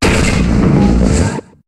Cri de Golemastoc dans Pokémon HOME.